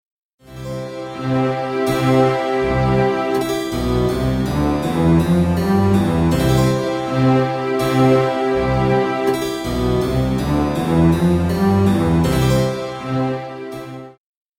Im folgenden Beispiel (Style BaroqueAir) wird im Fill-In B ein kurzes Motiv eingeflochten:
Beispiel 4a (Fill-In komplett)